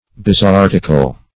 Search Result for " bezoartical" : The Collaborative International Dictionary of English v.0.48: Bezoartic \Bez`o*ar"tic\, Bezoartical \Bez`o*ar"tic*al\, a. [See Bezoardic .]